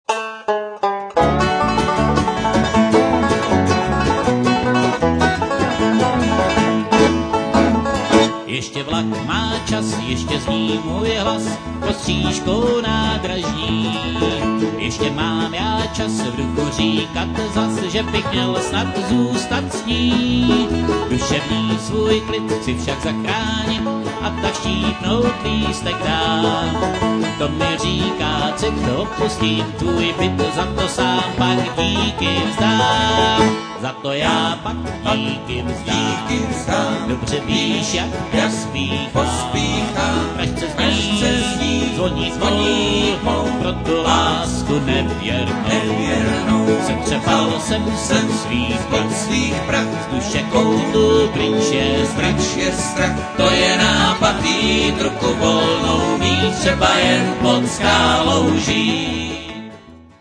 banjo
dobro
mandolin